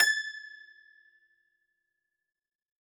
53q-pno21-A4.wav